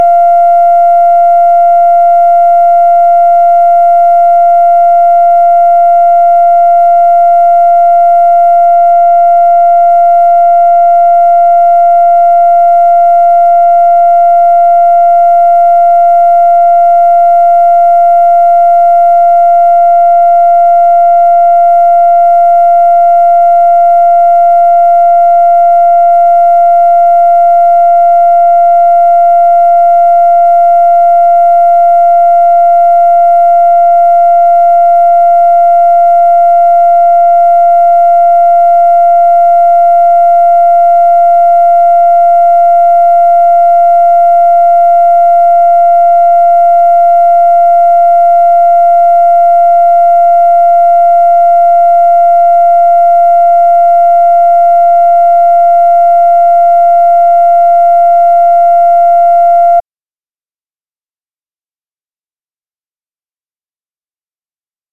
Conversation with WILLIAM RABORN, May 12, 1965
Secret White House Tapes